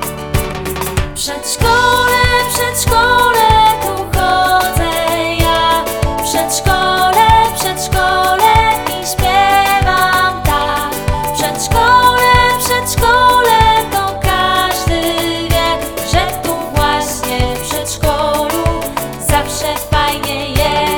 Pakiet zawiera wersję wokalną oraz instrumentalną. https